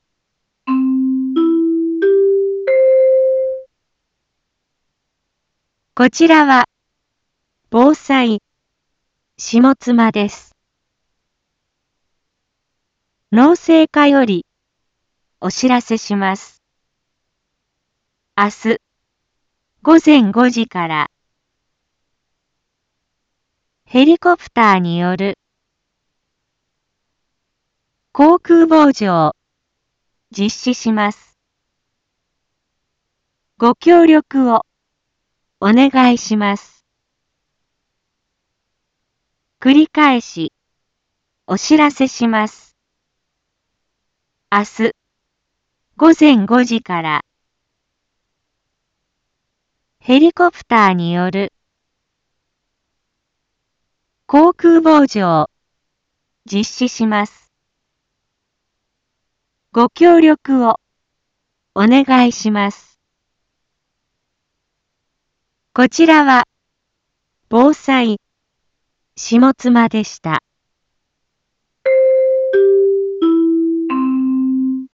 Back Home 一般放送情報 音声放送 再生 一般放送情報 登録日時：2021-07-24 18:01:15 タイトル：航空防除(総上,豊加美,千代川) インフォメーション：こちらは防災下妻です。